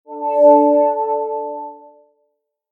notification_sounds
subtle.mp3